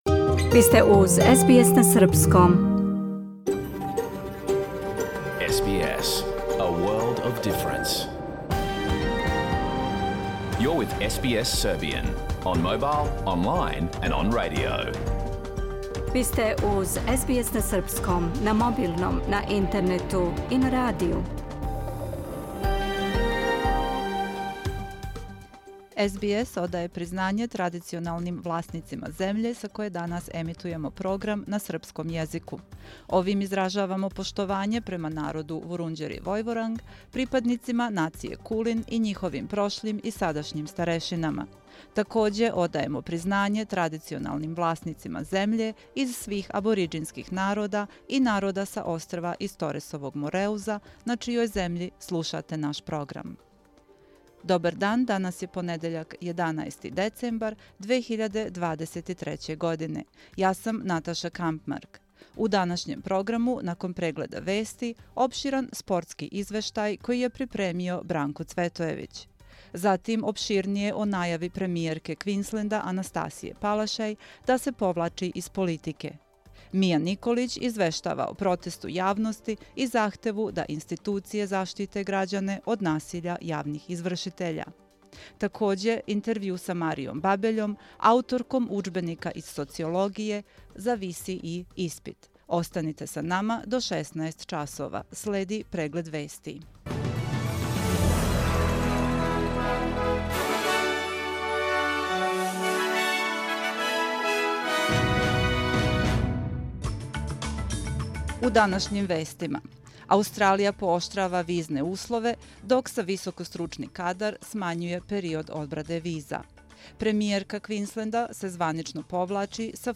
Програм емитован уживо 11. децембра 2023. године